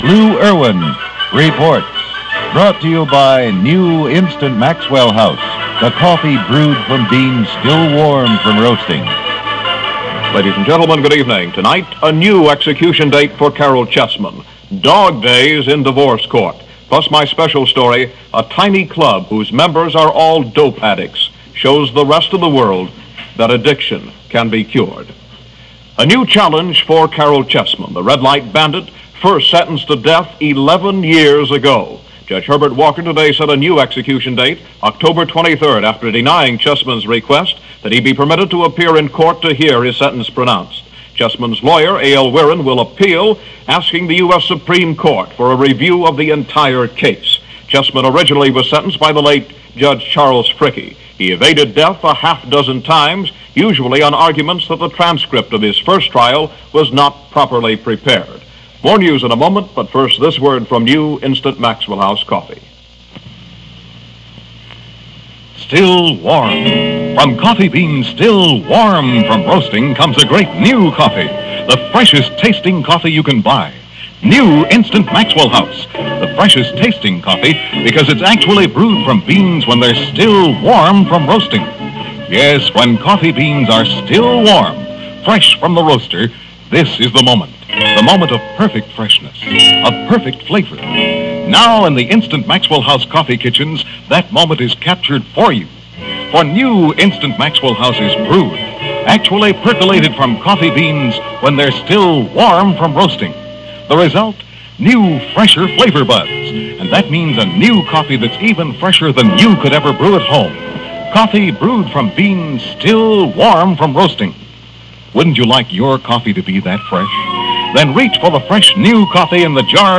News for a typical August day in 1959. For Los Angeles it meant scandals, trials, execution dates and the dog in Divorce Court.
Fascinating interviews